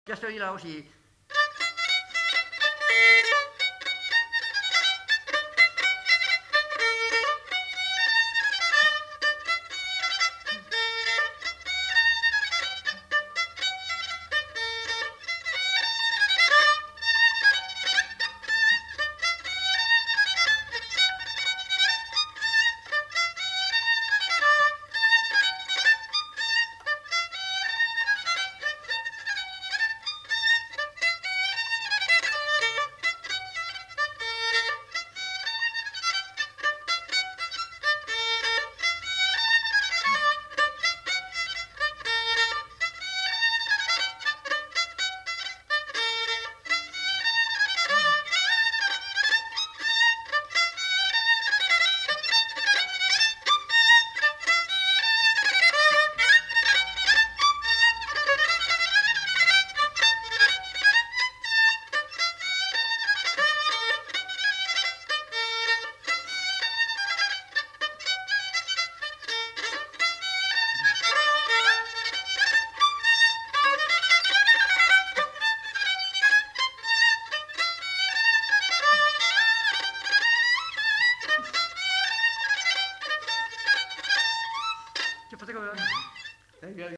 Lieu : Casteljaloux
Genre : morceau instrumental
Instrument de musique : violon
Danse : congo